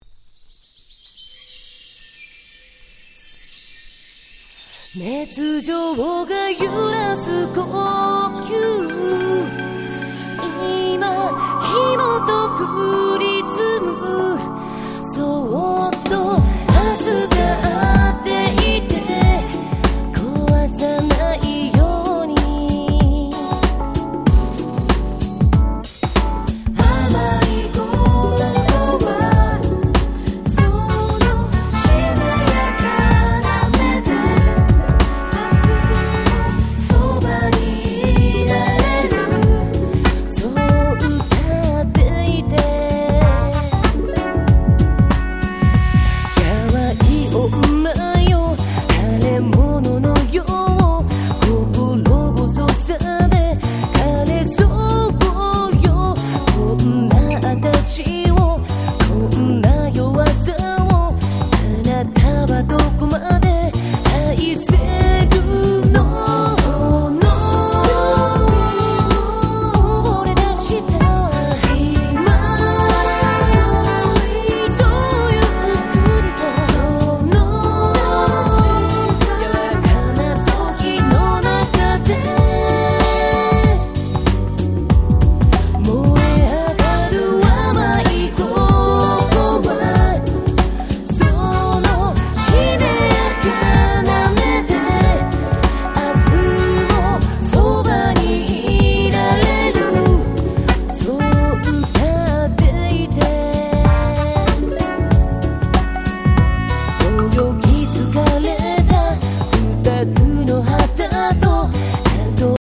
00' Nice Japanese R&B !!